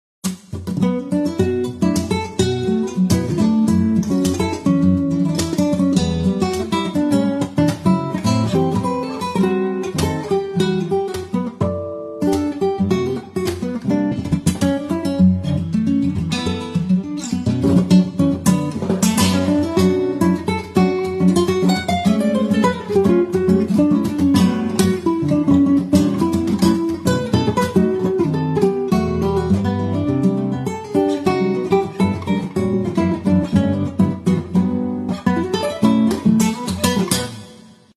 an original composition - Midnight dal segno